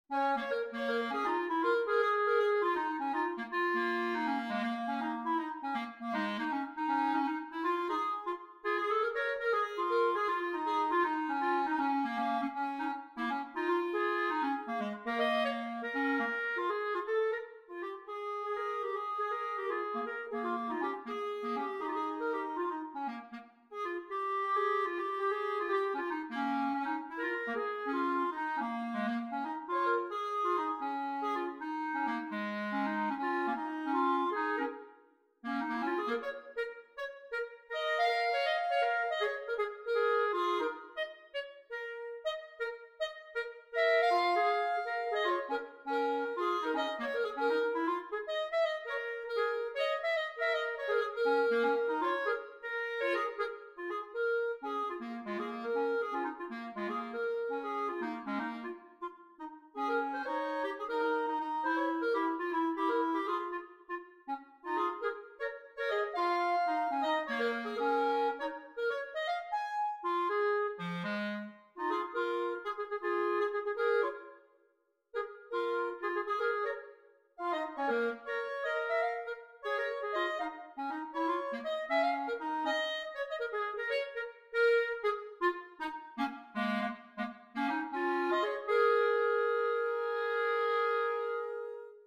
Gattung: Für 2 Klarinetten
Besetzung: Instrumentalnoten für Klarinette